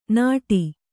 ♪ nāṭi